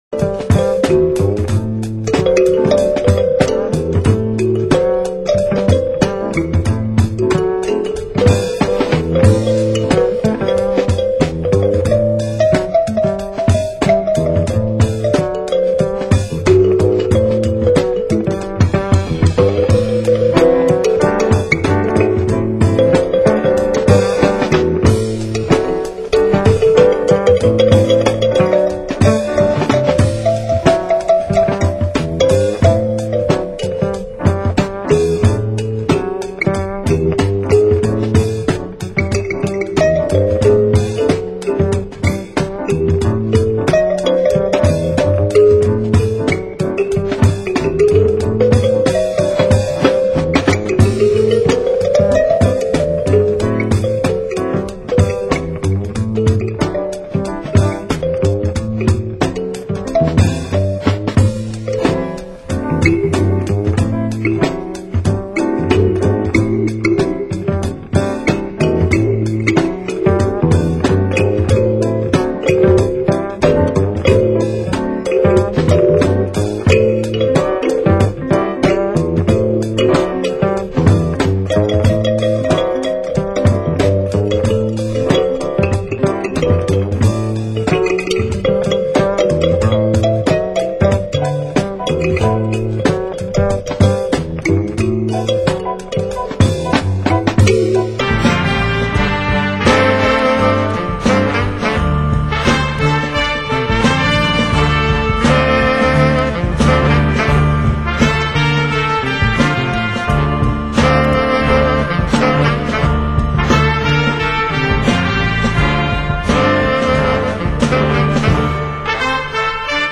Genre: Soul & Funk